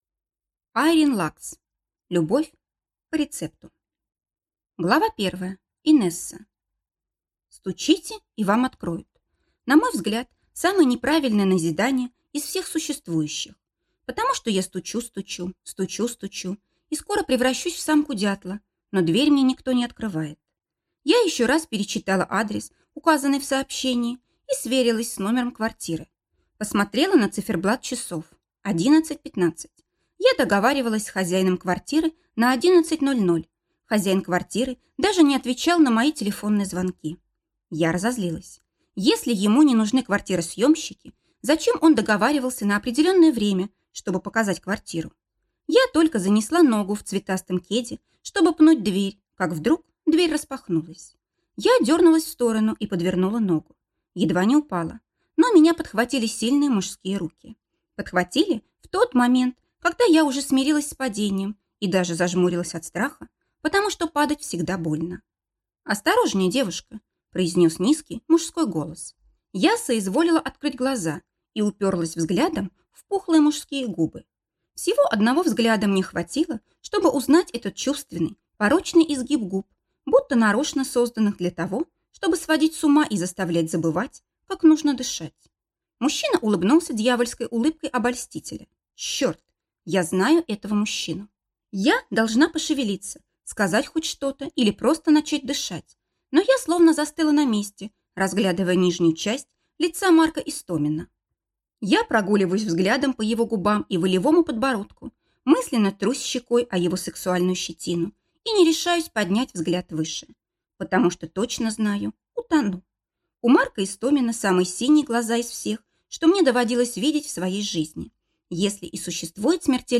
Аудиокнига Любовь по рецепту | Библиотека аудиокниг
Прослушать и бесплатно скачать фрагмент аудиокниги